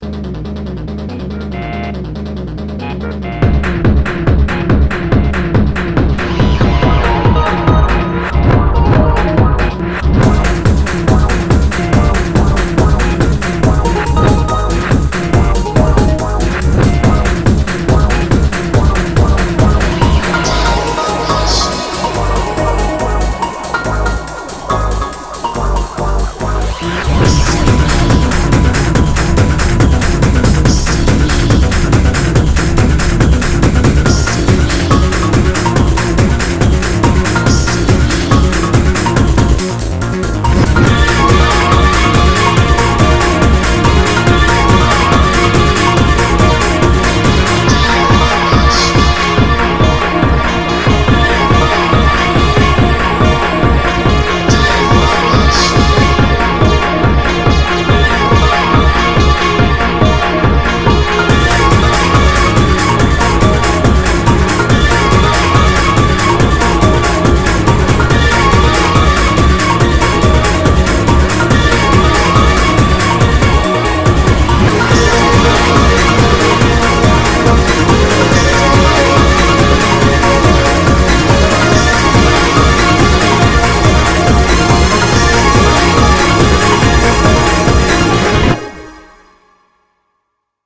BPM141
Audio QualityPerfect (High Quality)
A very cool and upbeat minimal track